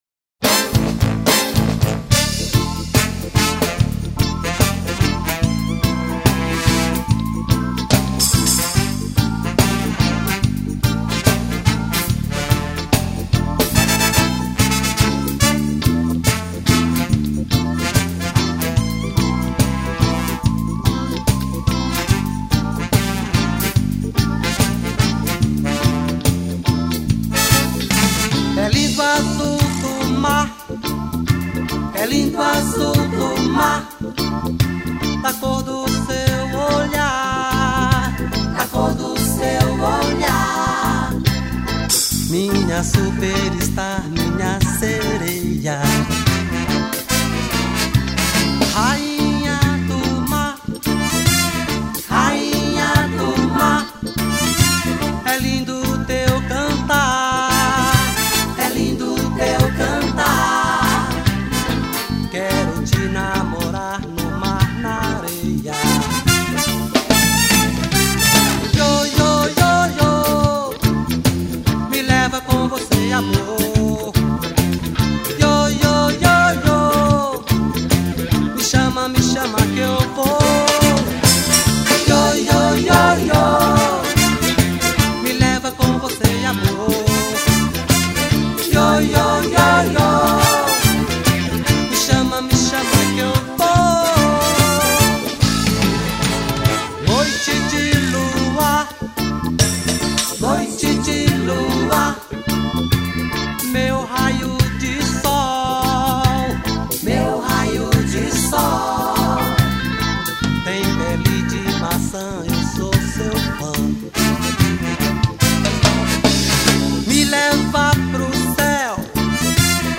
2133   03:48:00   Faixa:     Reggae